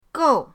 gou4.mp3